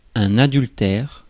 Ääntäminen
IPA: [a.dyl.tɛʁ]